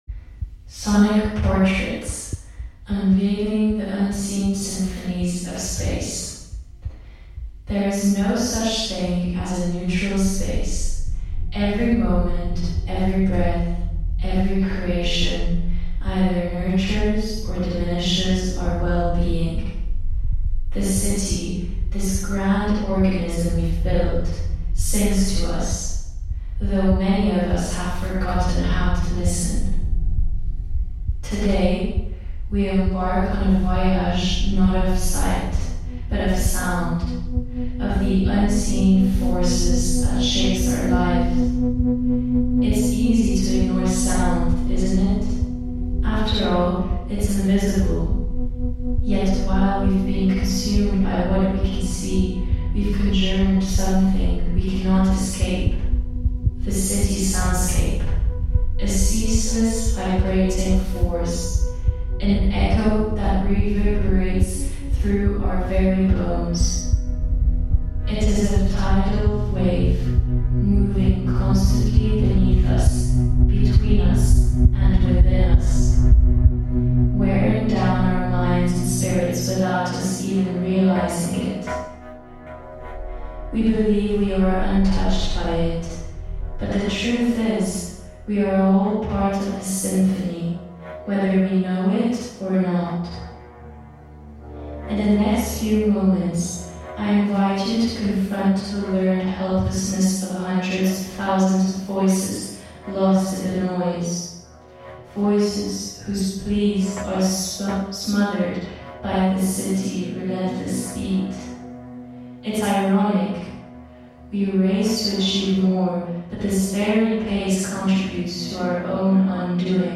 In a world where noise pollution has become the second-largest health threat in urban areas, this 30-minute soundscape shows how constant noise wears on our minds and bodies, contributing to stress, anxiety, and learned helplessness.
By blending overwhelming noise with pockets of stillness, the work encourages a return to the present moment - a chance to hear what’s often ignored.